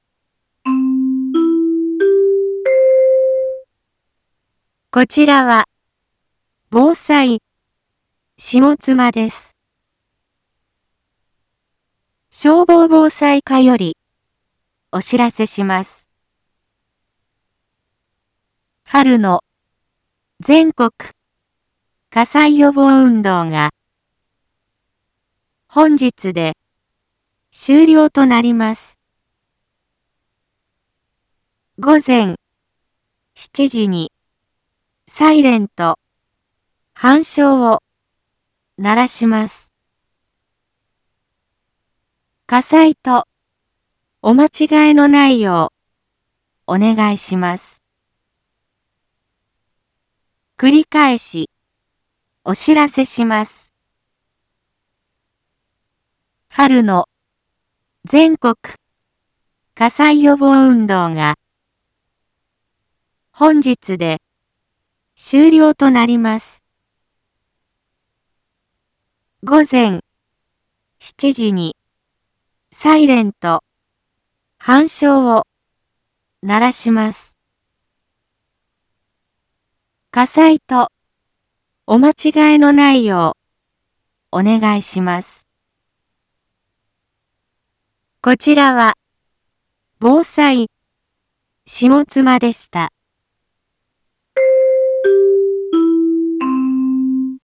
Back Home 一般放送情報 音声放送 再生 一般放送情報 登録日時：2025-03-07 06:46:56 タイトル：春季全国火災予防運動に伴うｻｲﾚﾝ吹鳴 インフォメーション：こちらは、ぼうさいしもつまです。